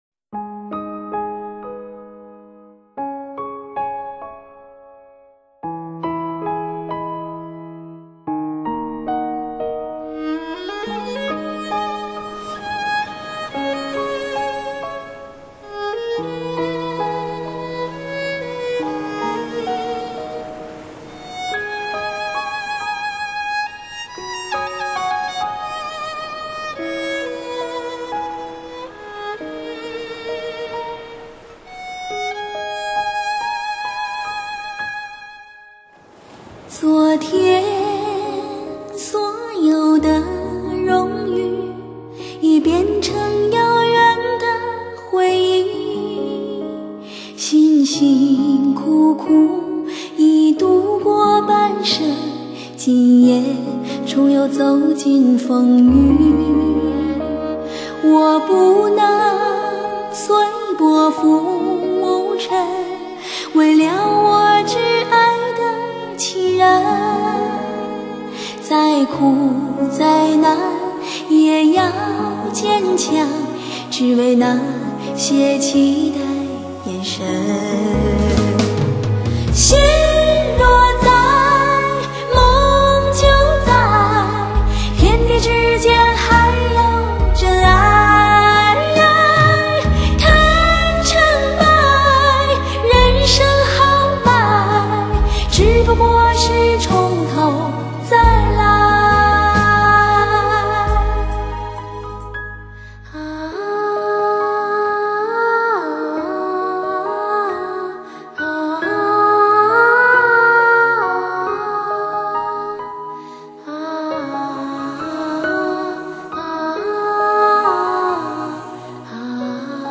辽阔、多情的旋律，就像马奶酒一样让人如痴如醉。